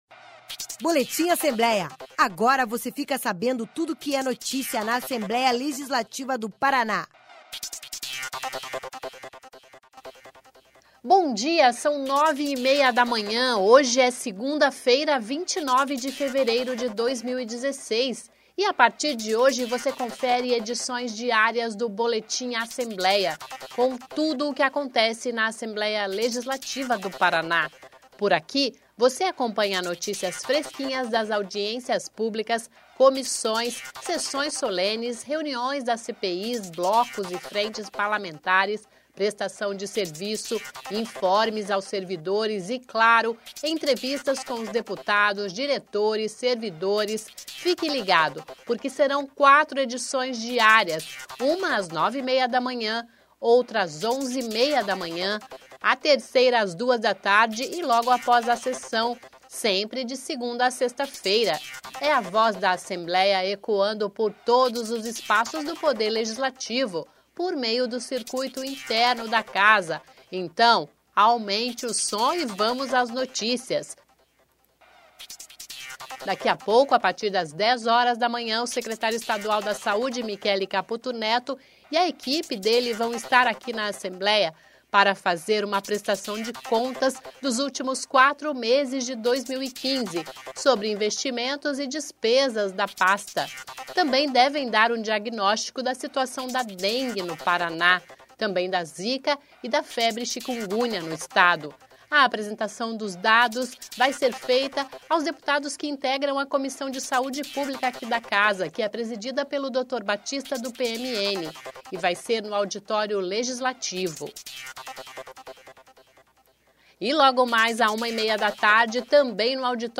A partir desta segunda-feira (29), entra no ar no circuito i8nterno de áudio da Assembleia Legislativa (Alep) o Boletim Assembleia.